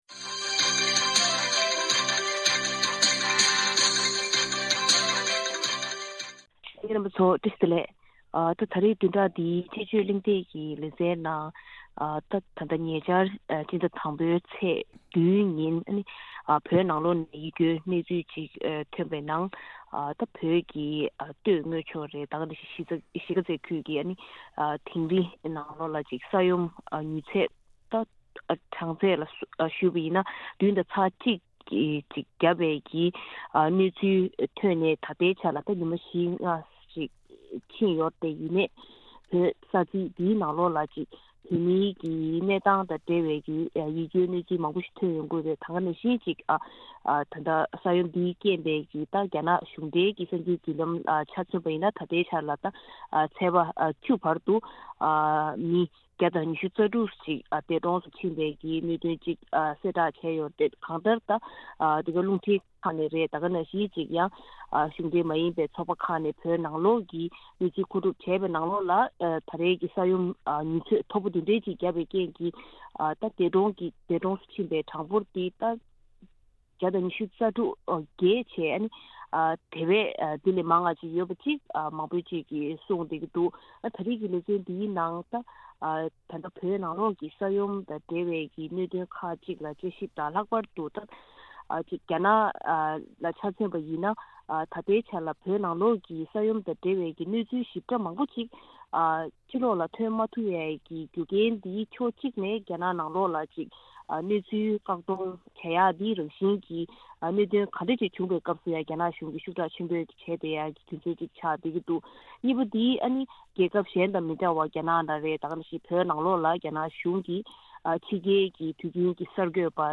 བདུན་ཕྲག་འདིའི་དཔྱད་བརྗོད་གླེང་སྟེགས་ཀྱི་ལས་རིམ་ནང་།